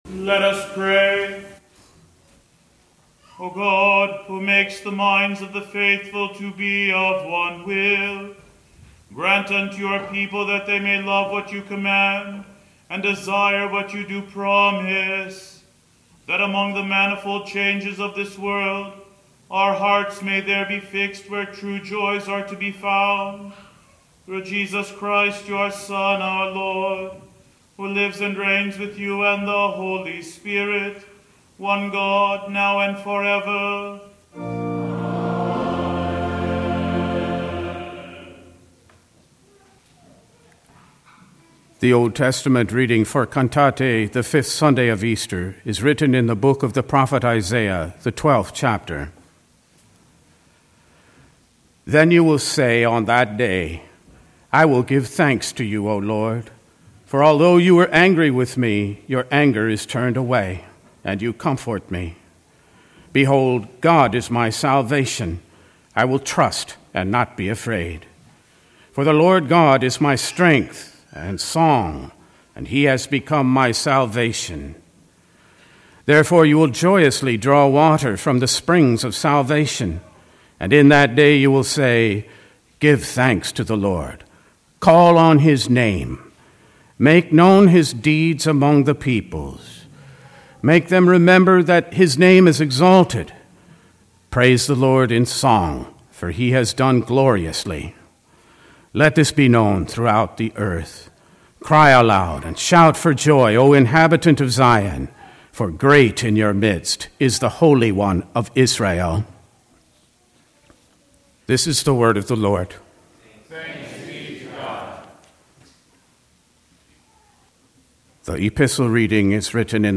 Sunday, April 28, 2024 (Cantate: Easter V) - Readings